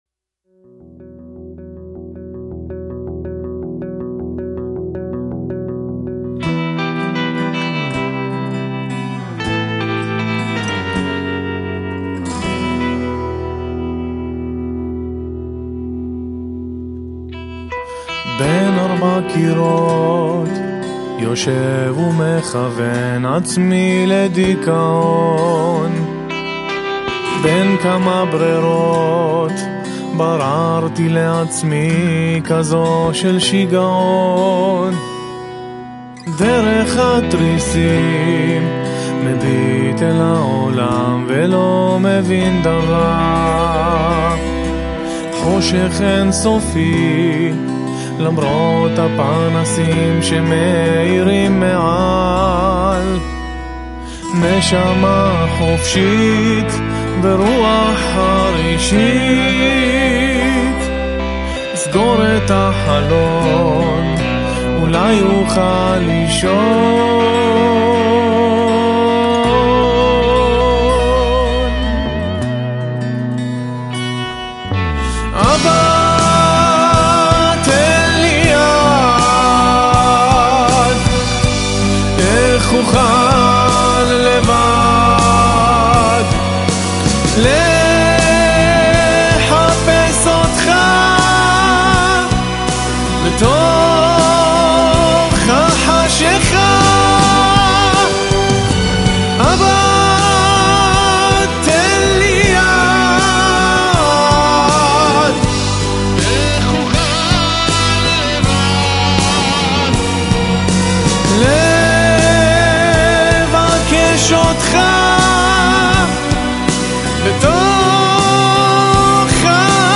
רוק יהודי בועט
כלי מיתר בשילוב תופים וגיטרות חשמליות